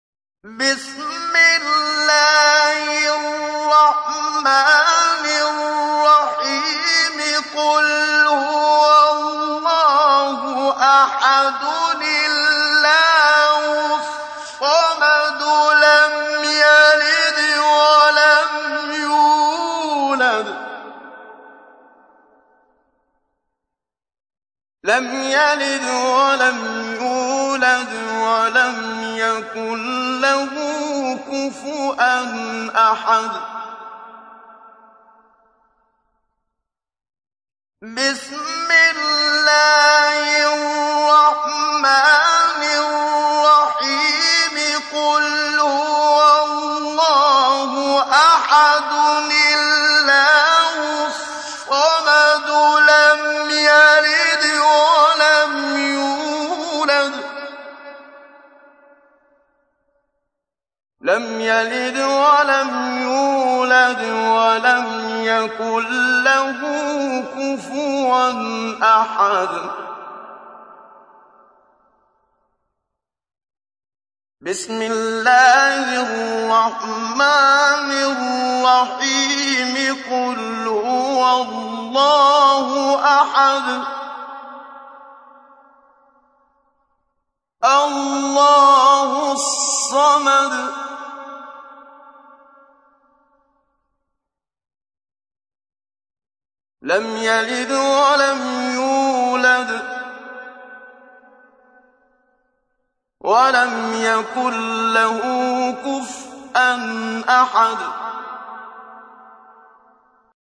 تحميل : 112. سورة الإخلاص / القارئ محمد صديق المنشاوي / القرآن الكريم / موقع يا حسين